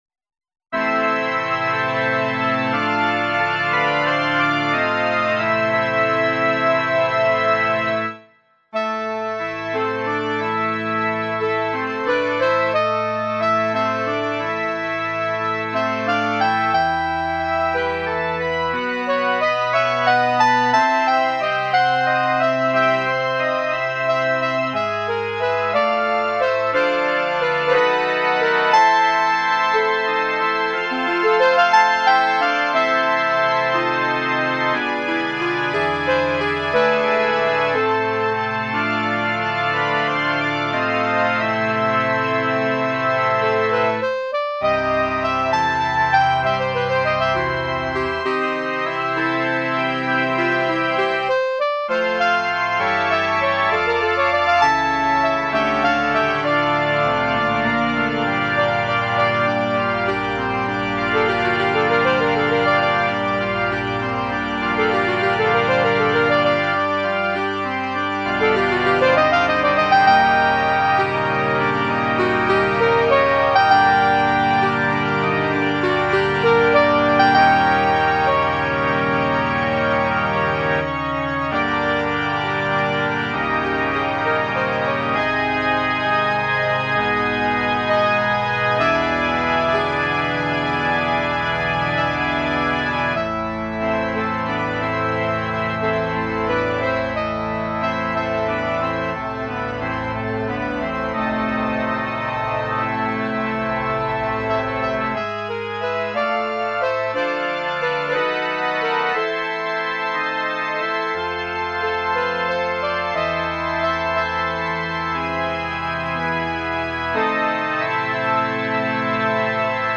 Besetzung: Instrumentalnoten für Klarinette